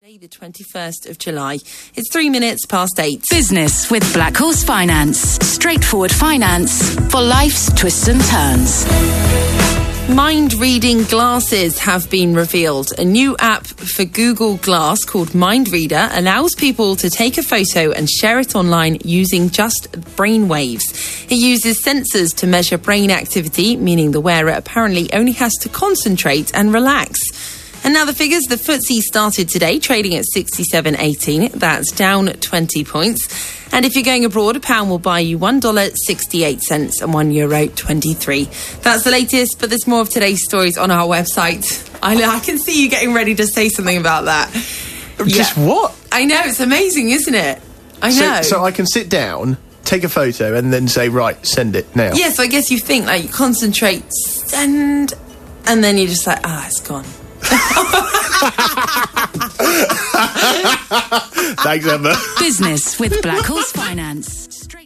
Trying to describe something over the radio is hard....sounds like I'm doing something very different!